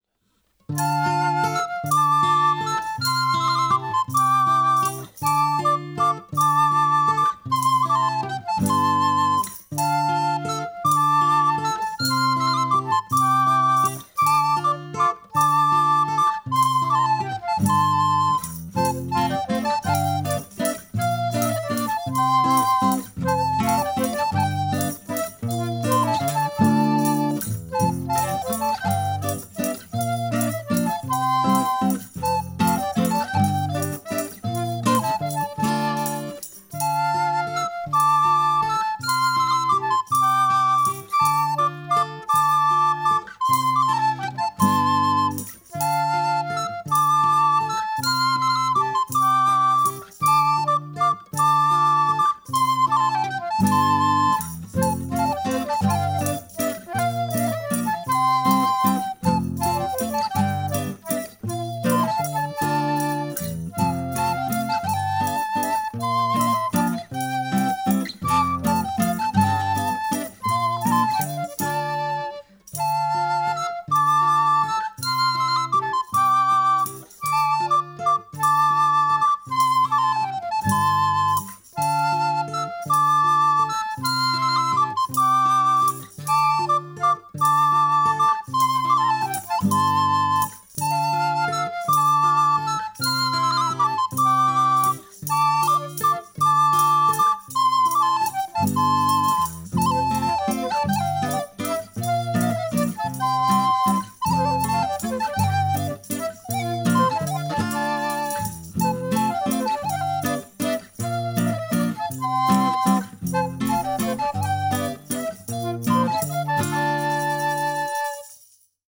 Evolutive Background music